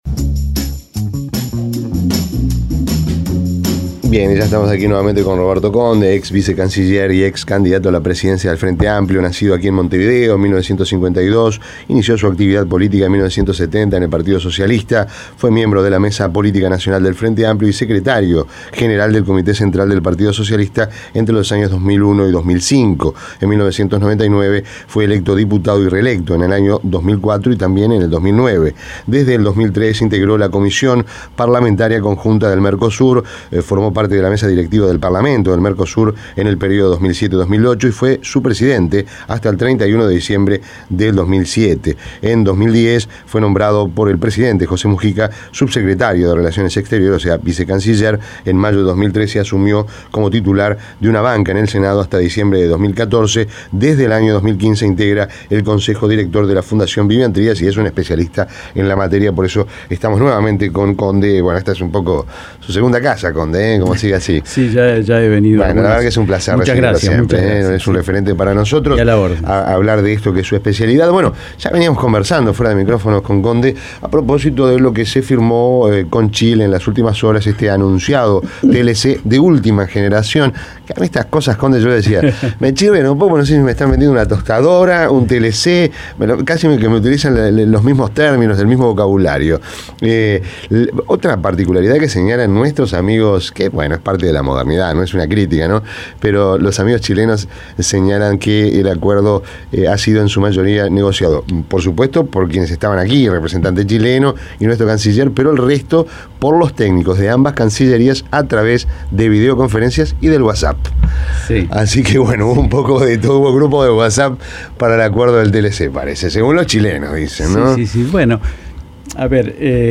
Entrevista en rompkbzas De TLCs y cangrejos Imprimir A- A A+ Este martes se firmó un Tratado de Libre Comercio (TLC) "de última generación" entre Uruguay y Chile, según lo calificó el canciller Rodolfo Nin Novoa.